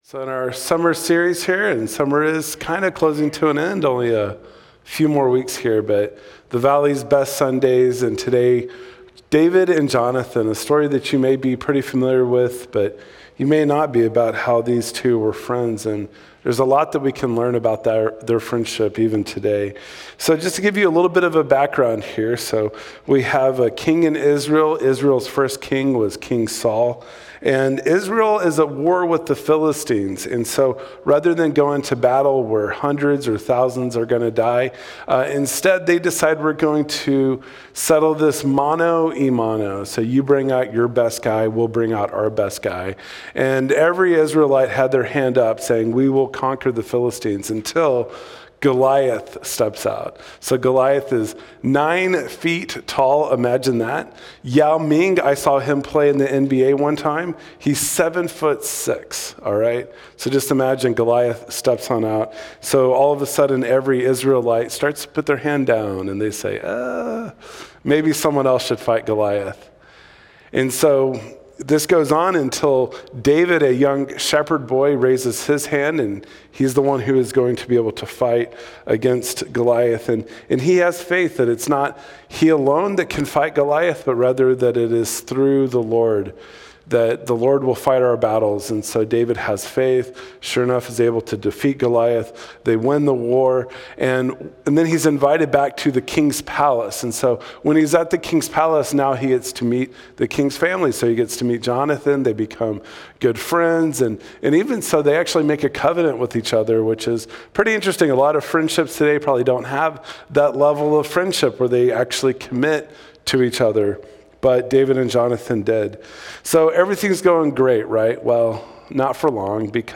07-27-Sermon.mp3